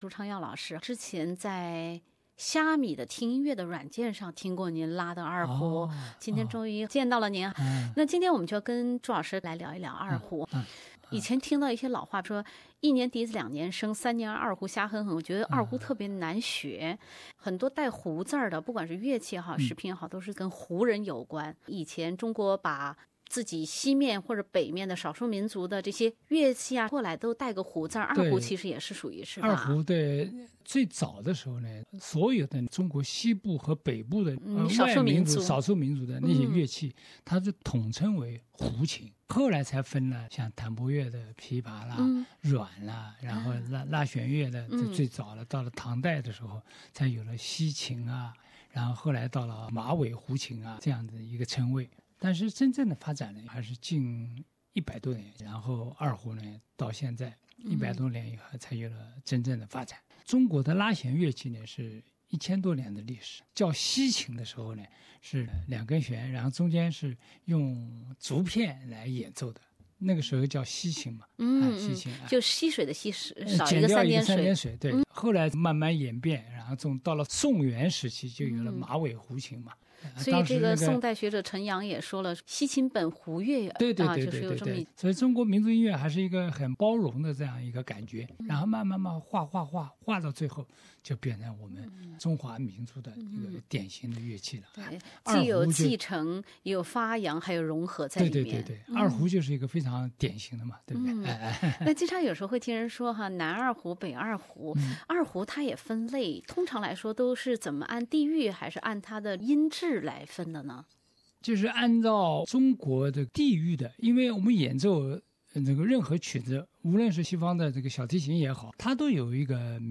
文化苦丁茶 - 文化就是“和而不同“，對話二胡大師朱昌耀